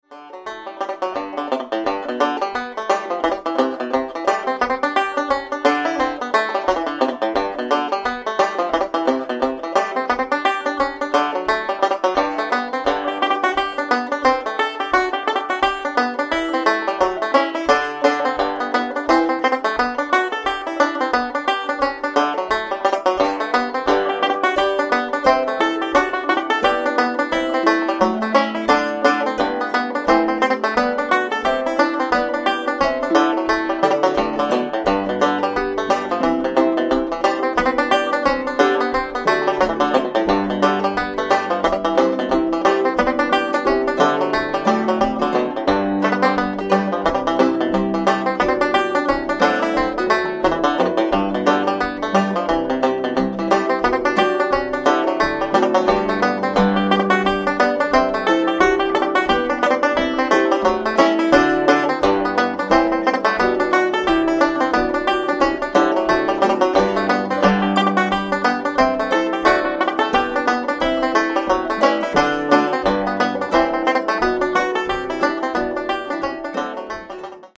light-handed, sparkling touch on the banjo